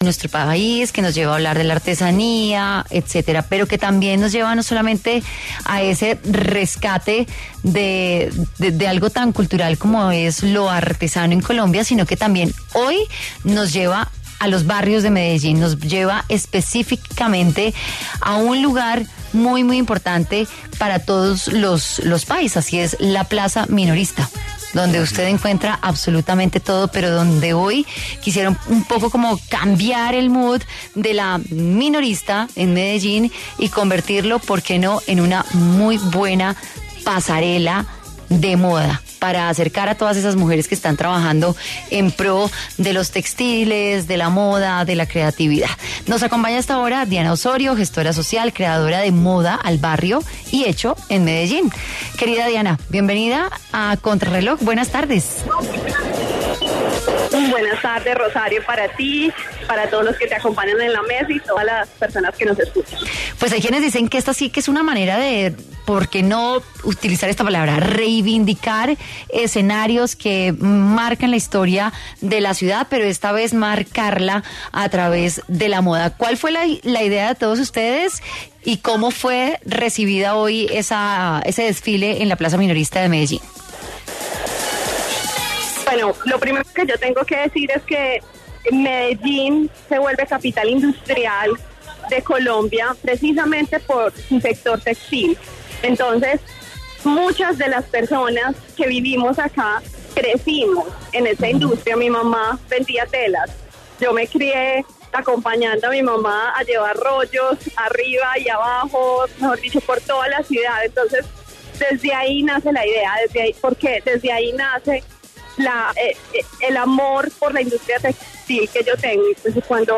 En entrevista con Contrarreloj de W Radio